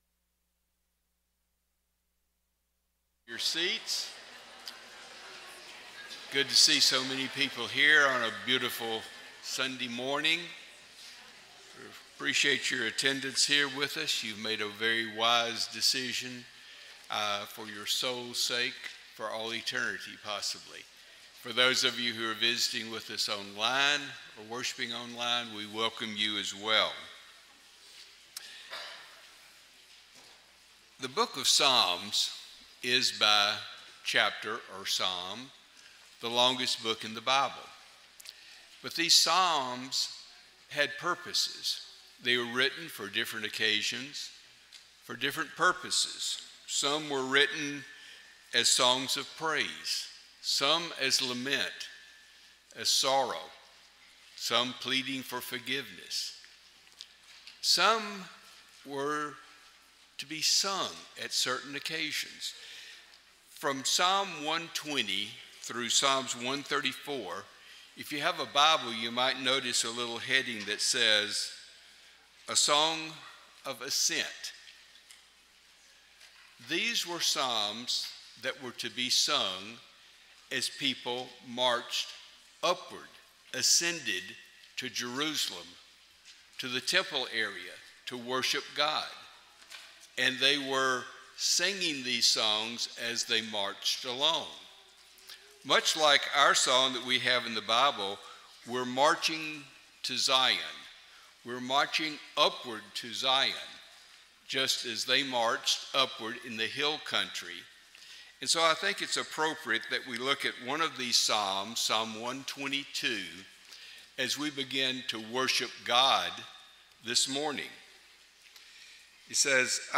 Acts 22:16, English Standard Version Series: Sunday AM Service